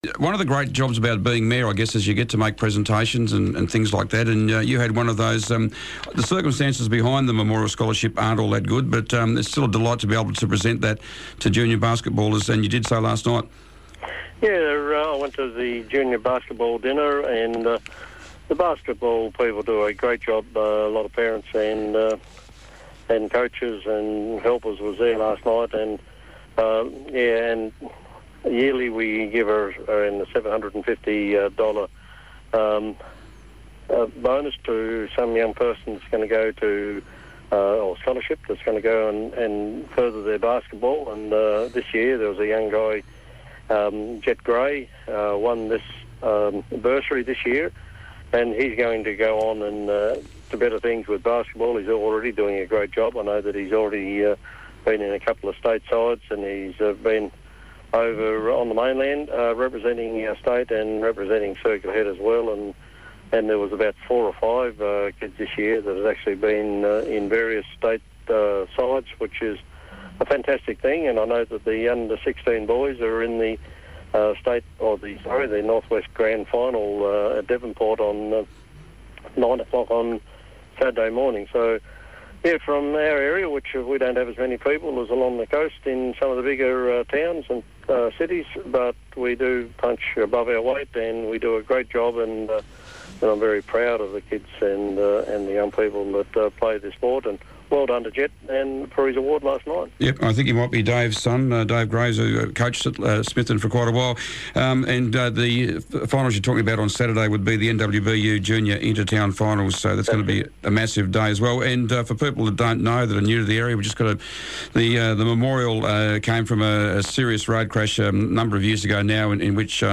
Circular Head Mayor Darryl Quilliam was today's Mayor n the Air. Darrly spoke bout a junior basketball scholarship, Science Week and a recent meeting with neigbouring councils and he Tasmanian Lieral Senate team . . .